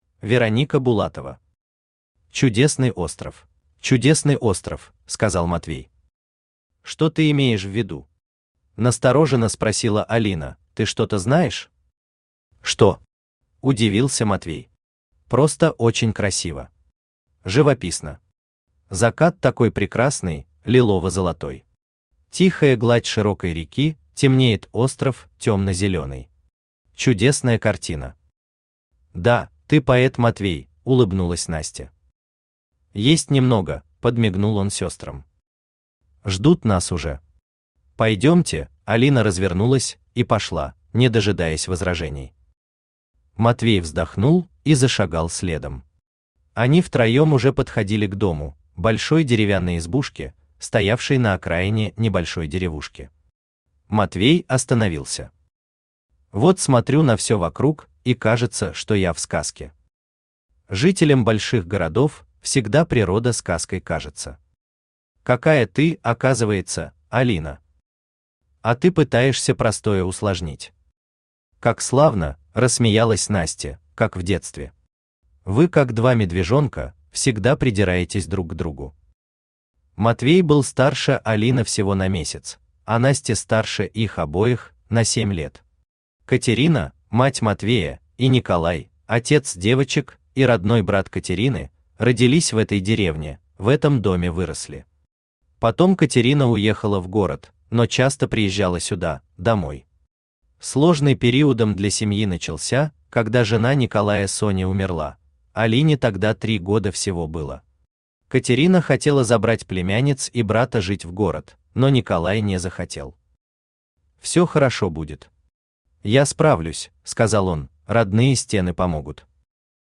Аудиокнига Чудесный остров | Библиотека аудиокниг
Aудиокнига Чудесный остров Автор Вероника Александровна Булатова Читает аудиокнигу Авточтец ЛитРес.